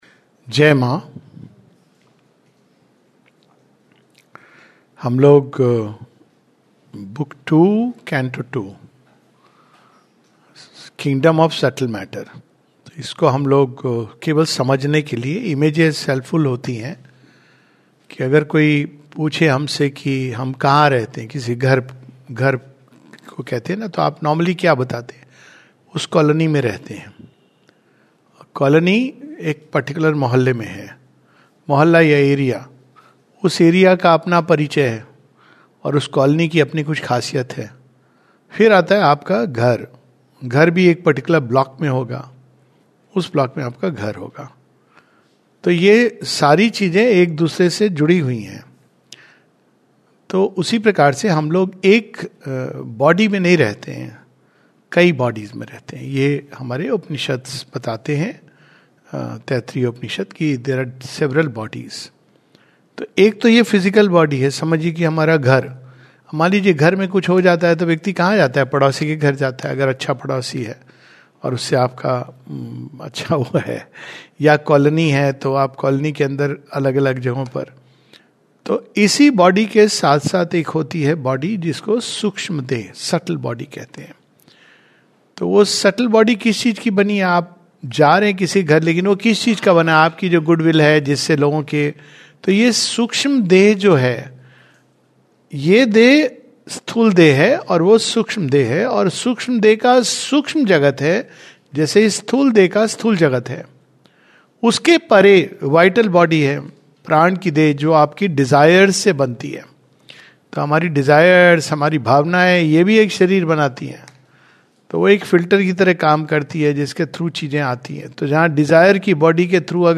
A Savitri Yagna class